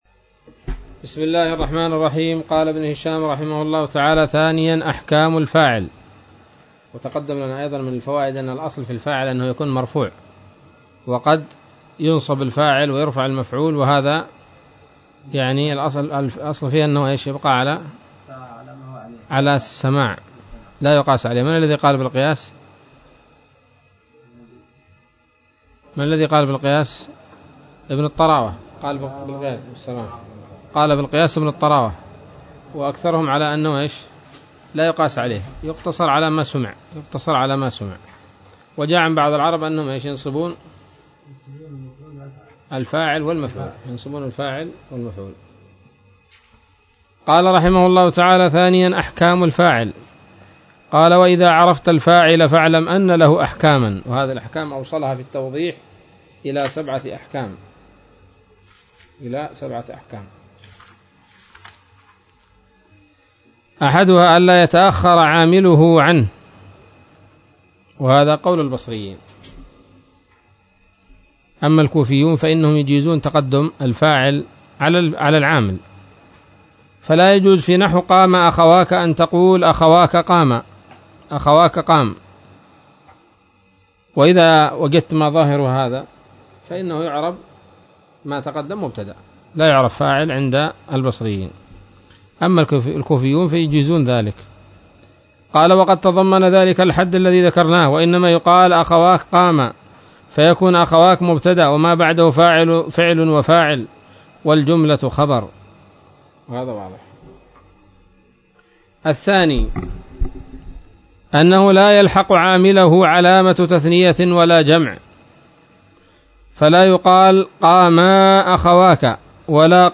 الدرس الرابع والسبعون من شرح قطر الندى وبل الصدى